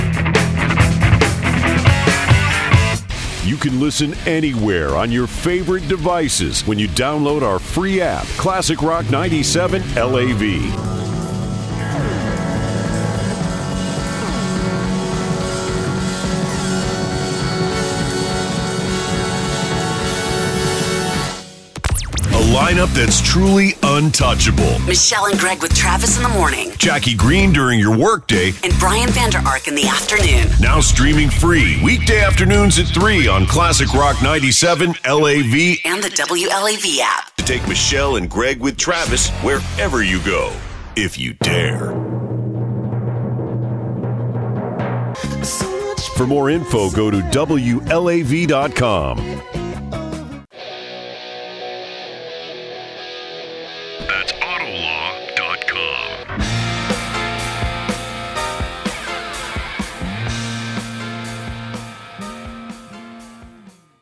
It’s a really good fit for my big, deep voice, and it feels very natural to bring the attitude and personality to the type of station I’m voicing for.
Radio Imaging: Rock, News, Sports
WLAV-Demo.mp3